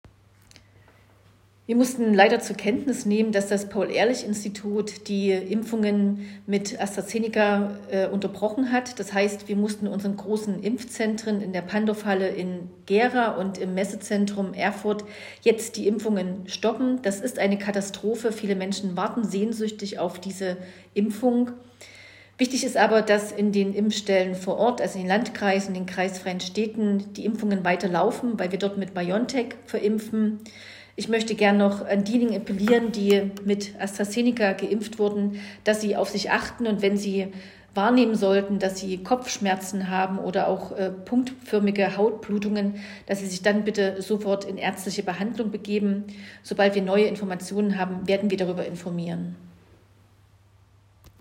Statement der Miniserin Heike Werner:
Statement-Werner-Impfstoff-AstraZeneca.m4a